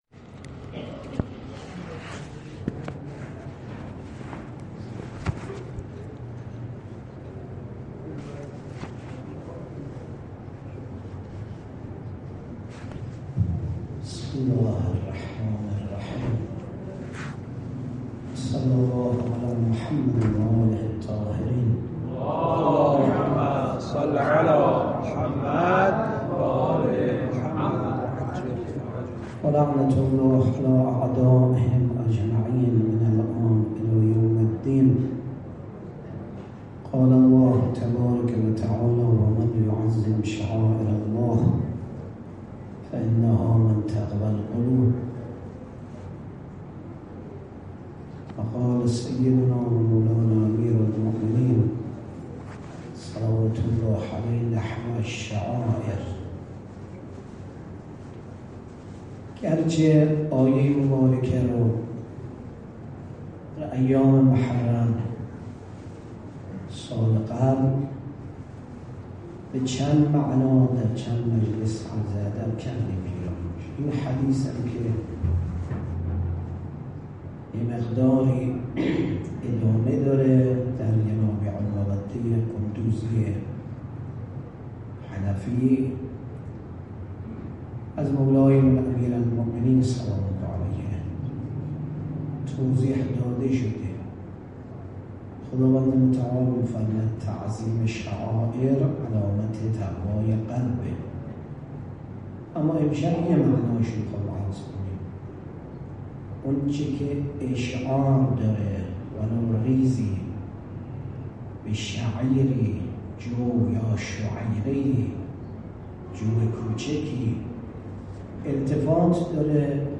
16 مرداد 96 - هیئت الهادی - آماده شدن برای عیدالله الاکبر